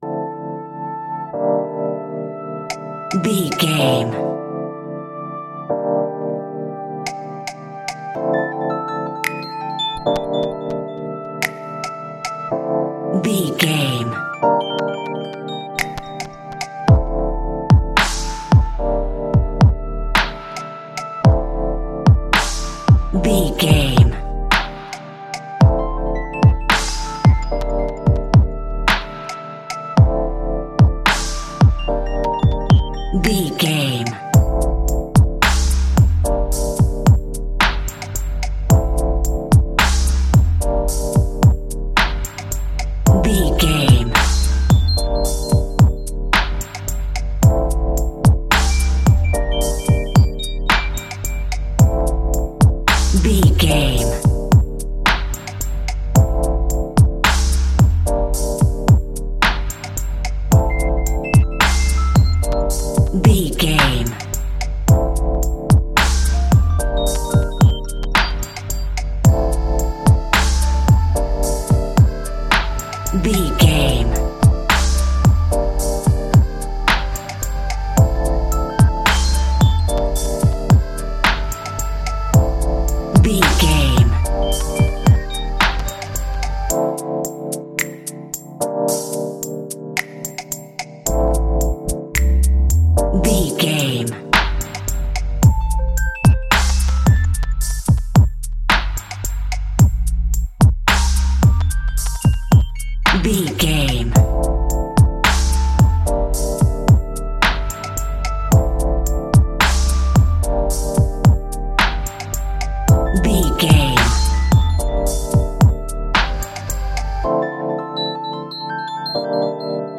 Aeolian/Minor
Slow
dreamy
tranquil
mellow